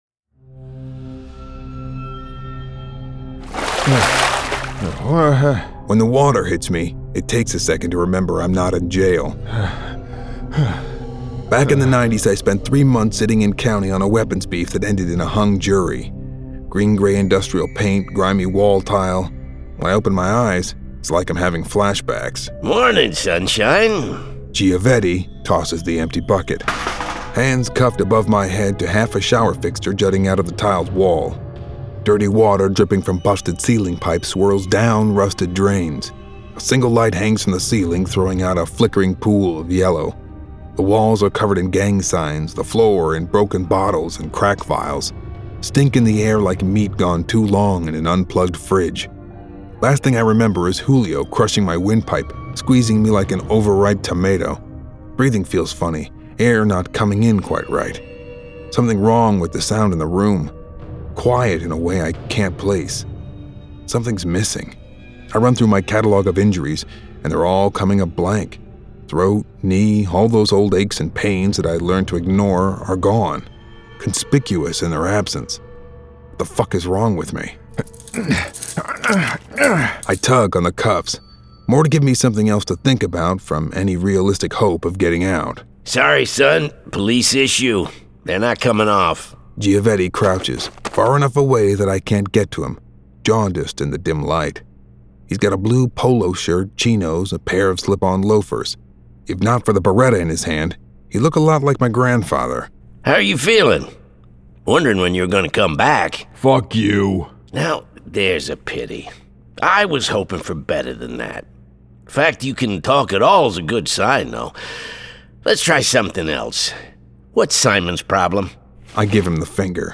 City of the Lost [Dramatized Adaptation]